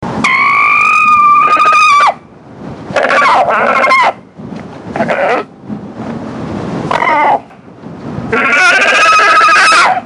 Screech From An Unhappy Alpaca
Low, guttural sounds expressing: ✔ Food competition ✔ Mild annoyance ✔ Establishing pecking order
Screech-From-An-Unhappy-Alpaca.mp3